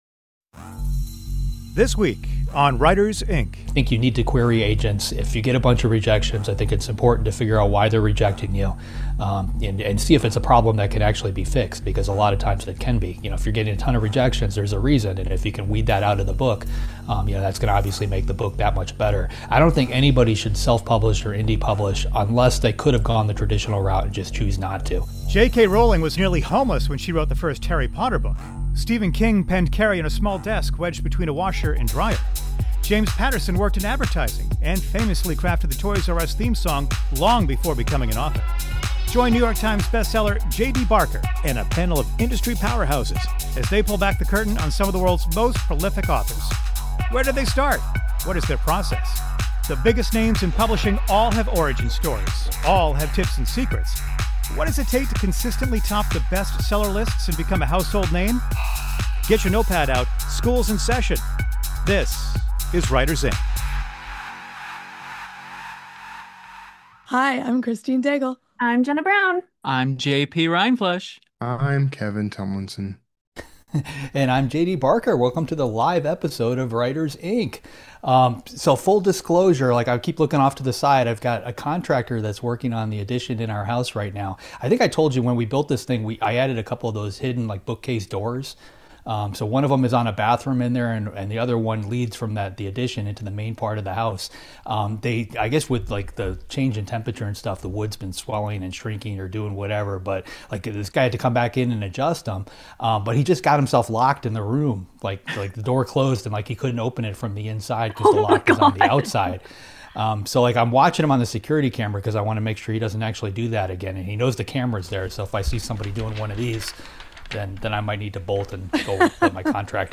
The Writers, Ink. Live Episode!